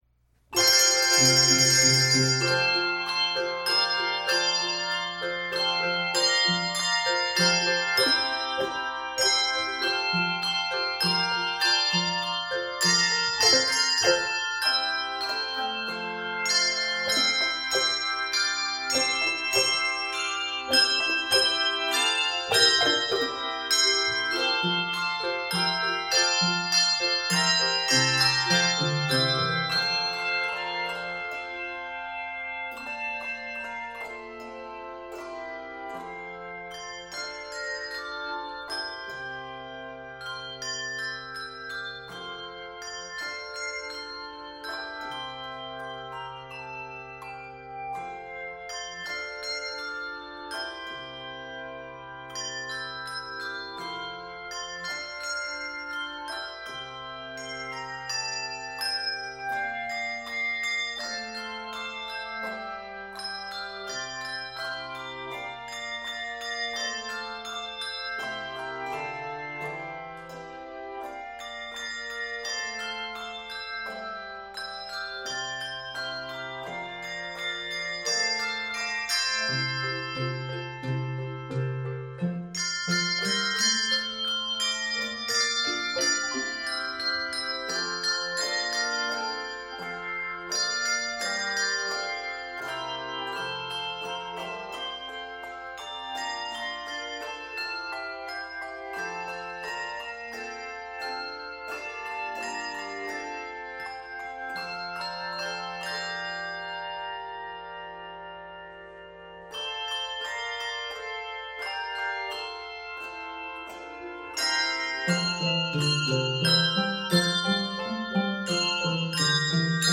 contemporary worship song